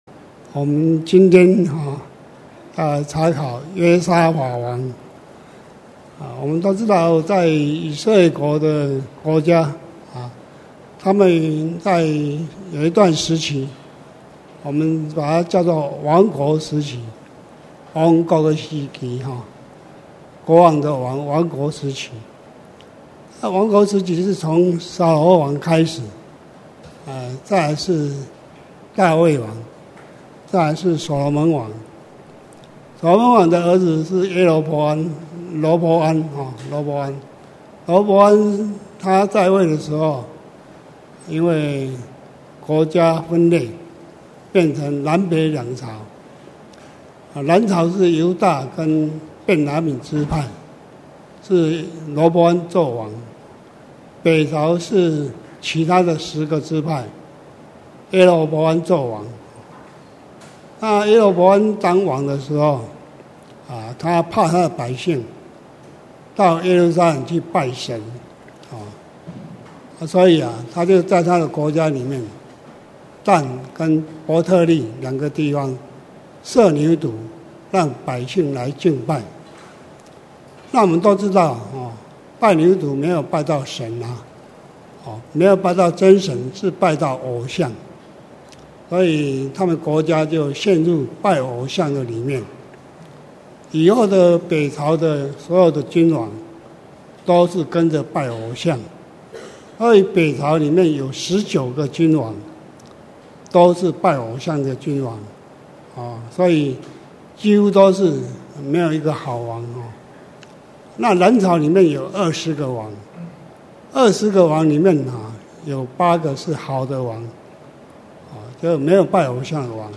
2017年11月份講道錄音已全部上線